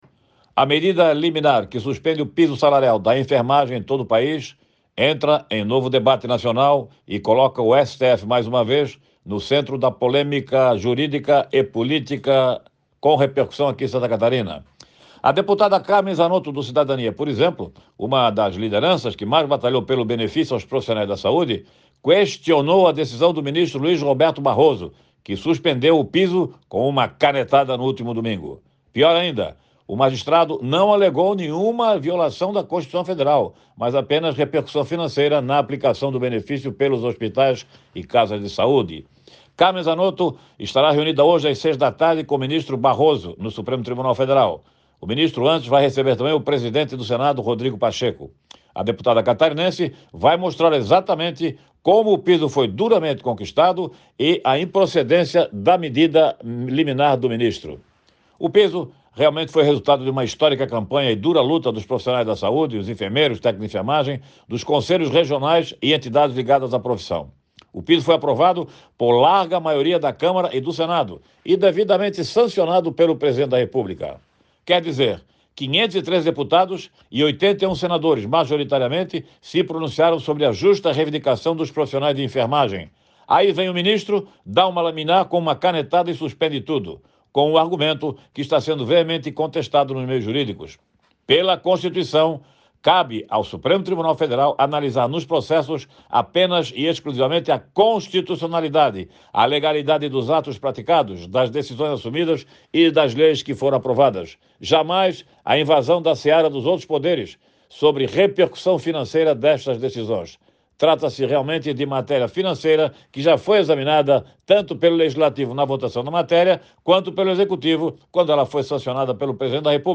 Comentarista destaca a repercussão no estado da liminar que suspende o piso salarial para os profissionais da saúde emitido pelo Ministro Barroso